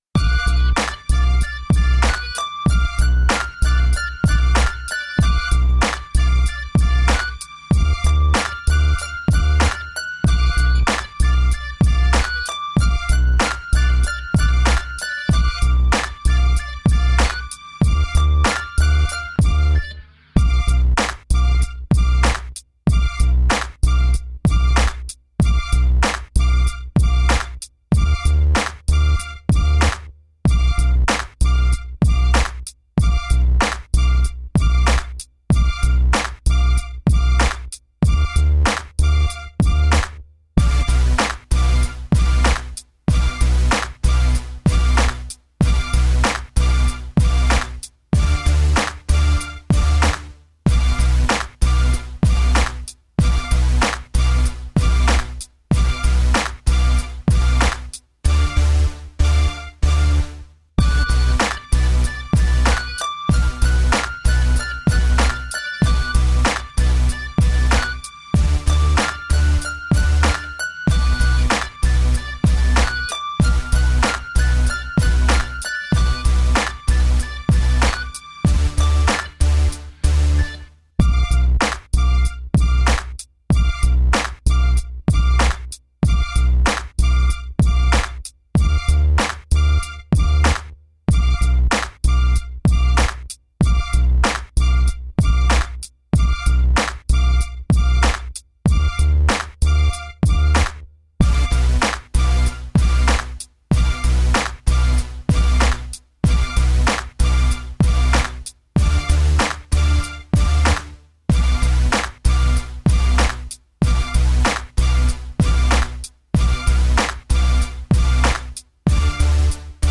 Dub, reggae, electronica, downtempo, chill out, trip hop.